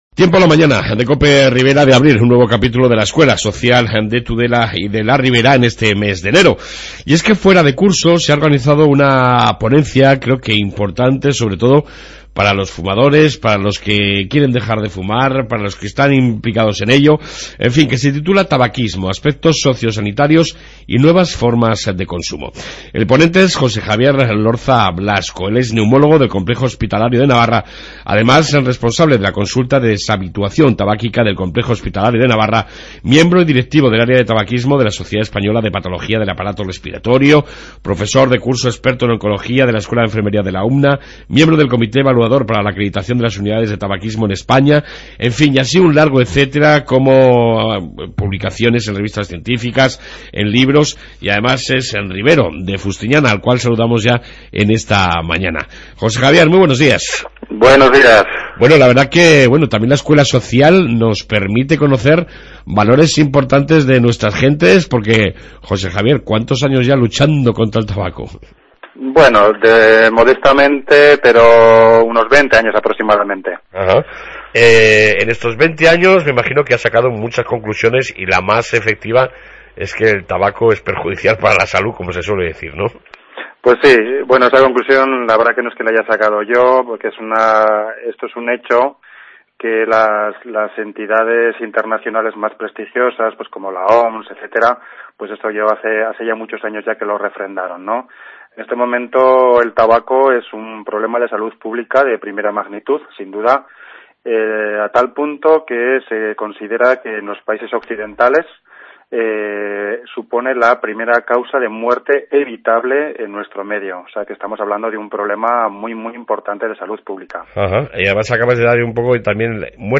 AUDIO: En esta 2ª Parte entrevista sobre:Tabaquismo: Aspectos socio-sanitarios y nuevas formas de consumo”